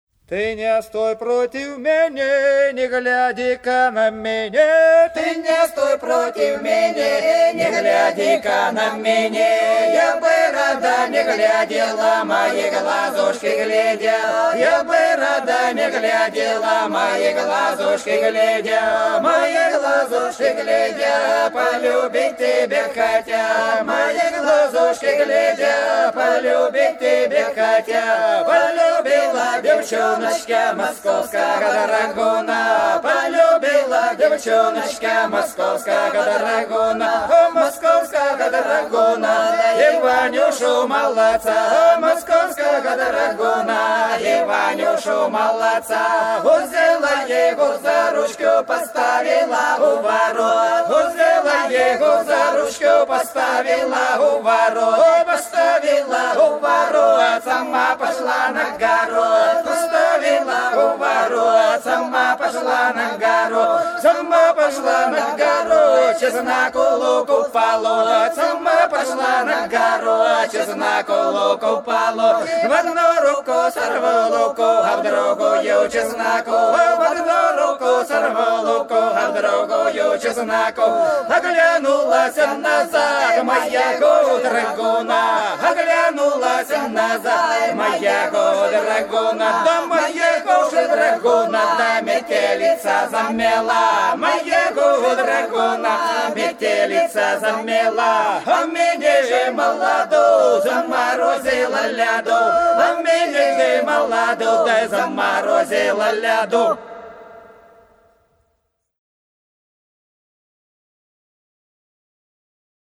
Долина была широкая (Поют народные исполнители села Нижняя Покровка Белгородской области) Ты не стой против меня - плясовая